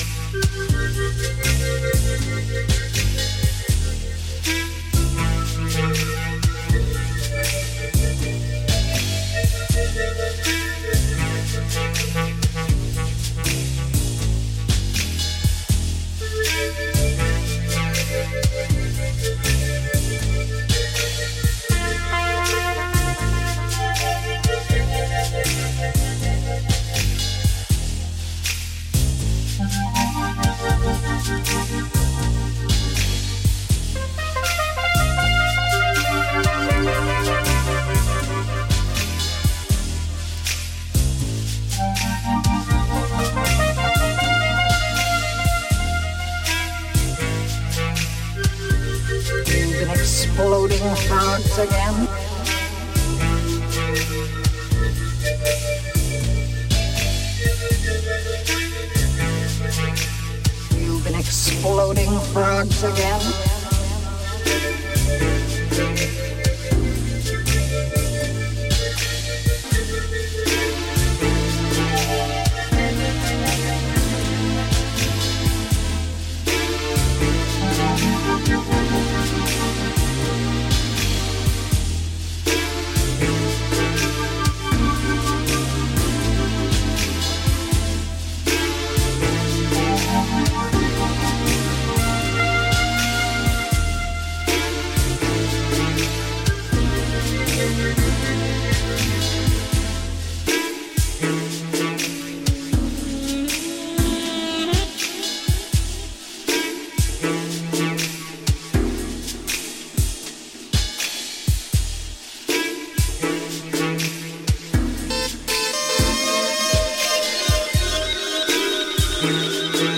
slightly new agey, progressive house type thing''.
Electro Electronix House Soundtrack